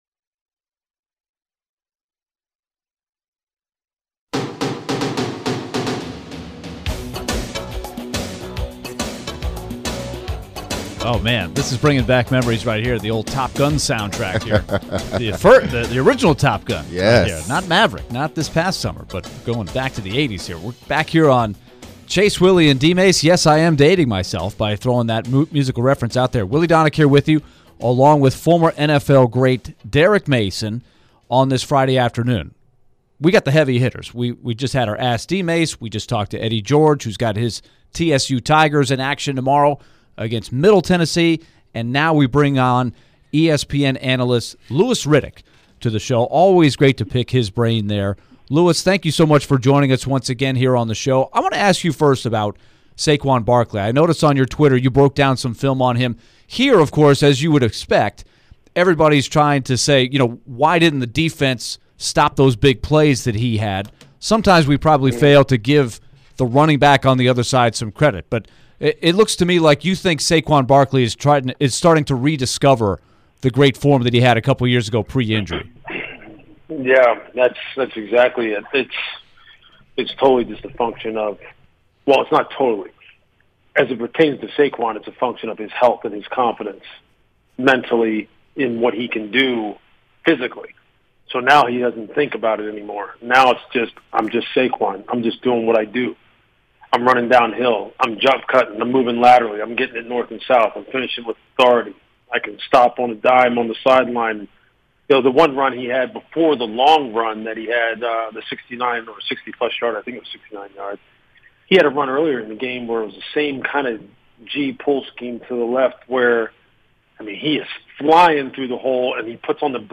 Louis Riddick interview (9-16-22)